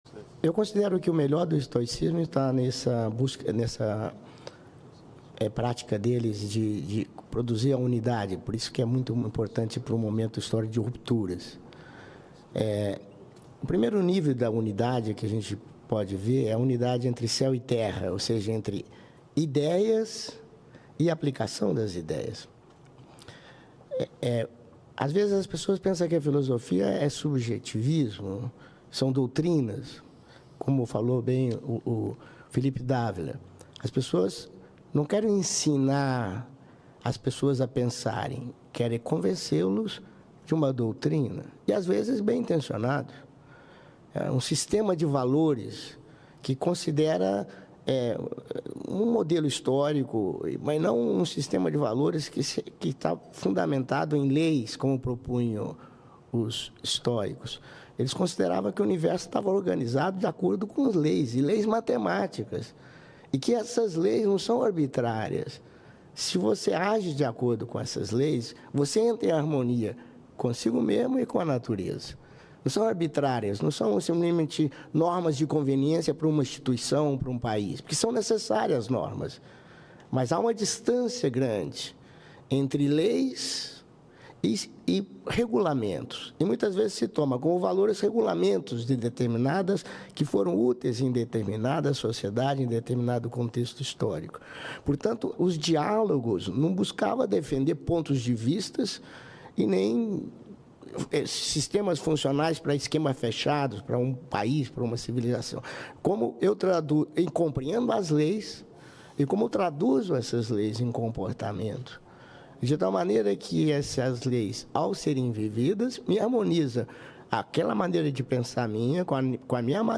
Na segunda-feira, dia 18 de setembro, a Organização Internacional Nova Acrópole participou da sessão especial em homenagem ao Estoicismo no plenário do Senado Federal em Brasília.